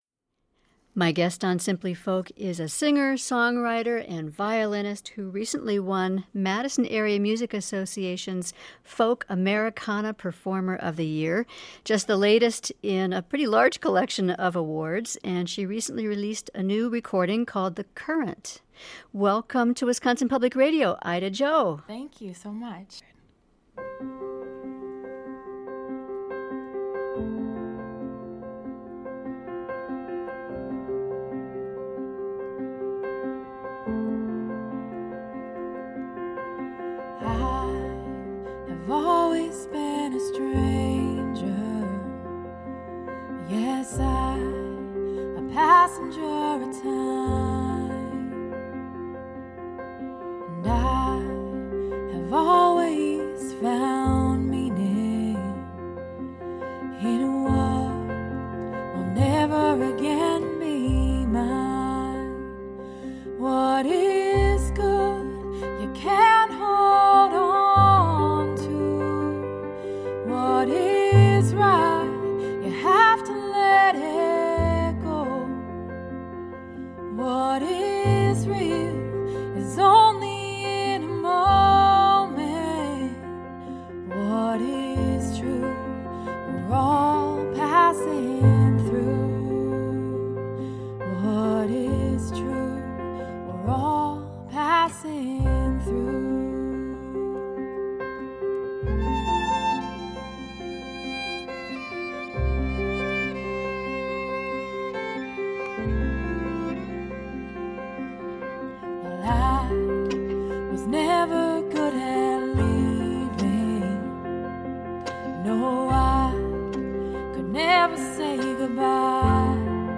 Taped in October 2014 at Vilas Hall.